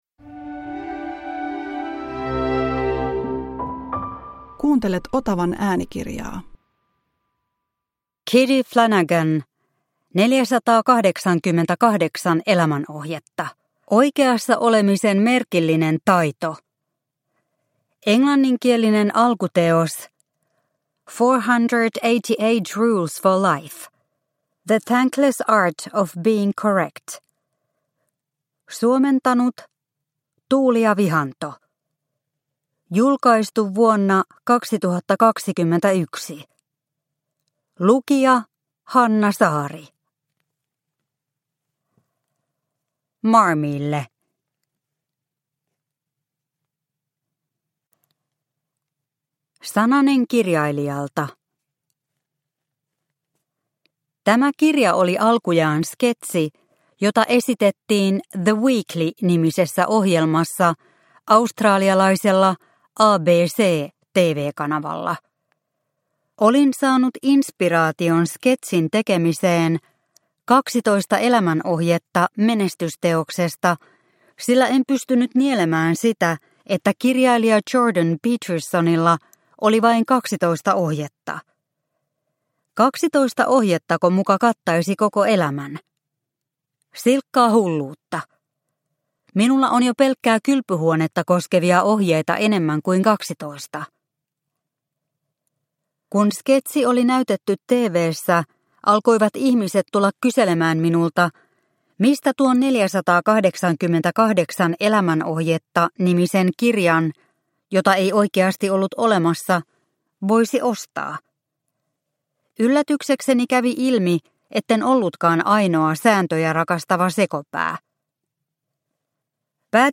488 elämänohjetta – Ljudbok – Laddas ner